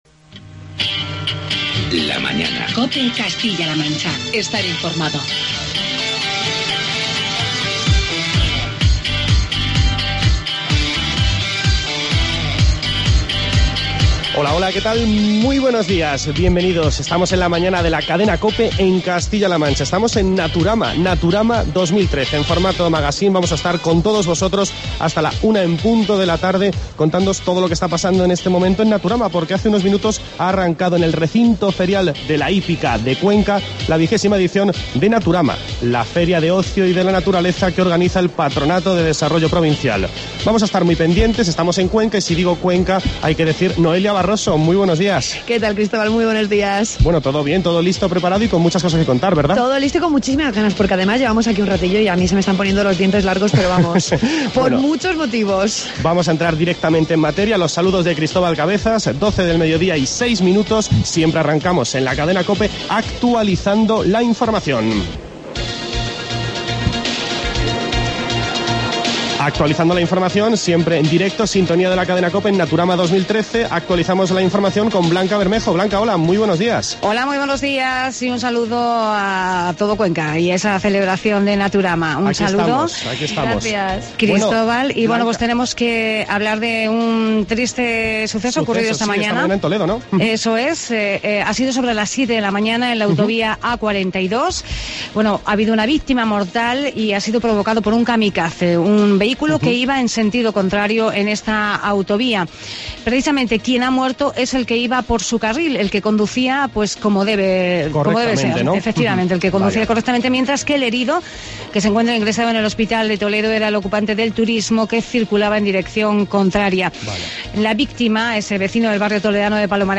AUDIO: Ya puedes escuchar el programa especial de La Mañana en Castilla-La Mancha desde la feria Naturama.